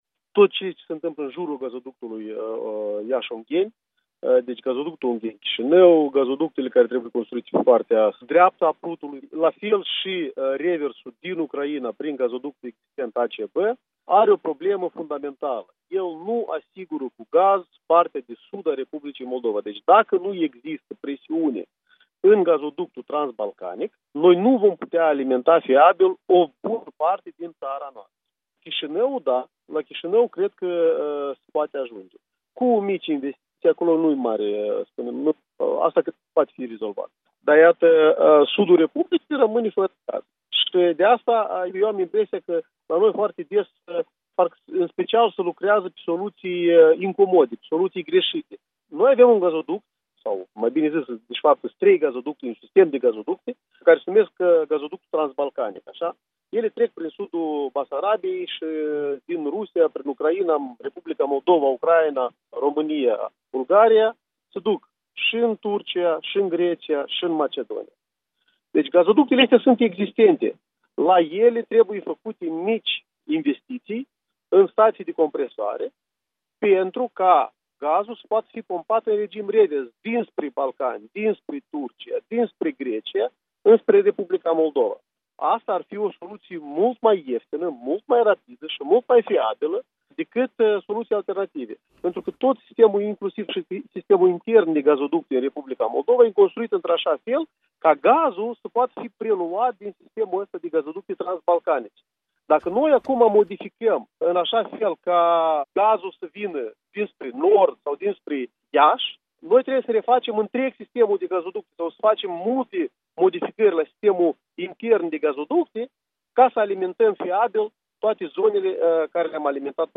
Interviul dimineții la EL: cu Victor Parlicov